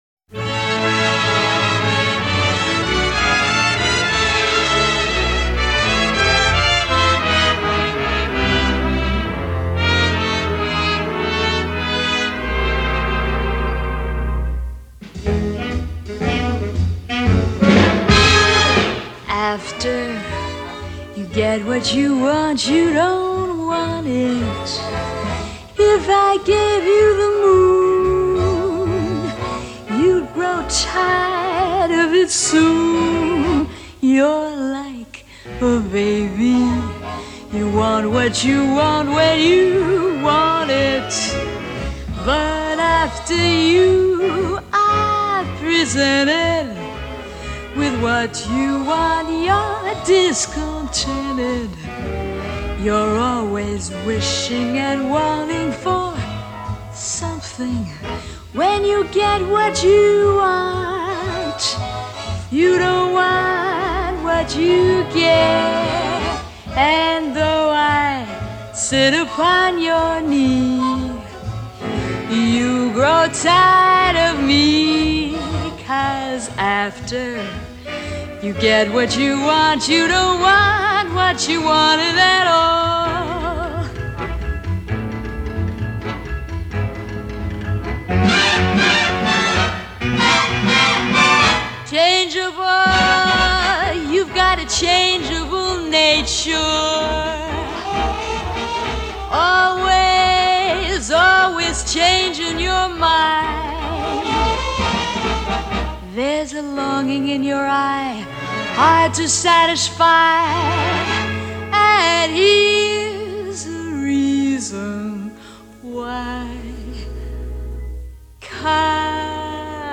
Her singing was wispy and sexy.